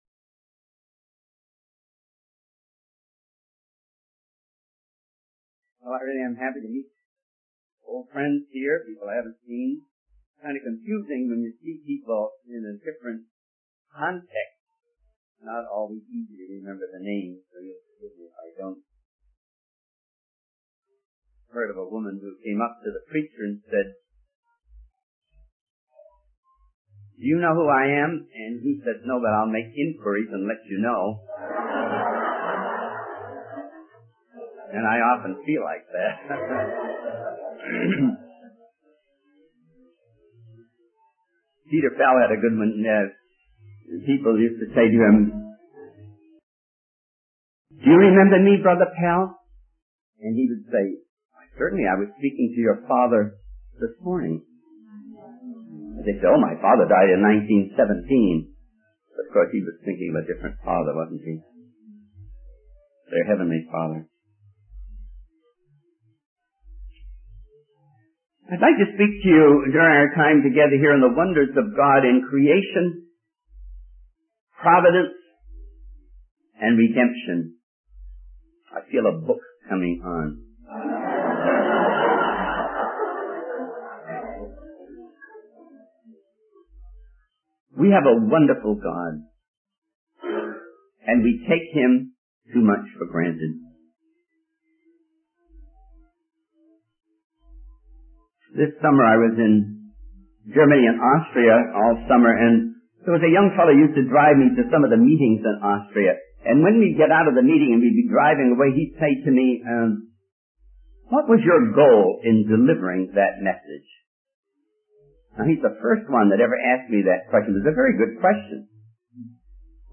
In this sermon, the speaker reflects on the wonders of God in creation and emphasizes the need to have greater thoughts of God.